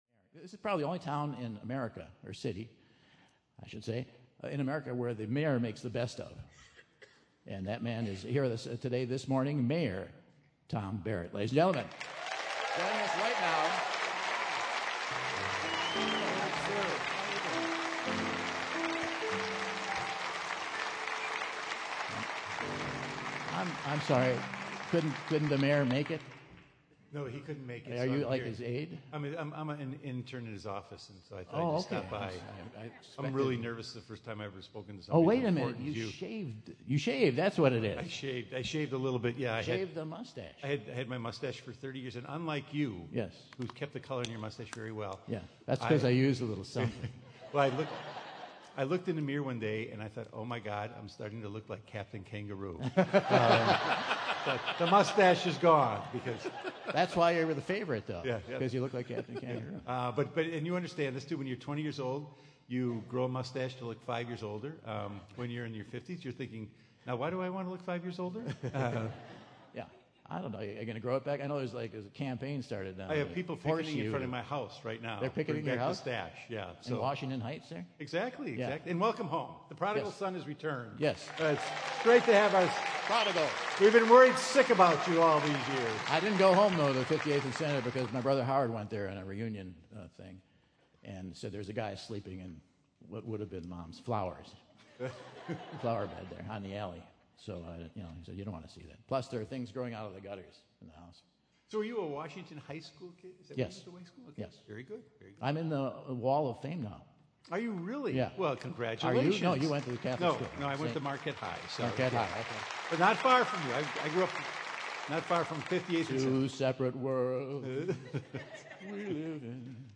Milwaukee mayor Tom Barrett fills Michael in on why he's now sans mustache and the two of them trade hometown tales and share their love for the Cream City!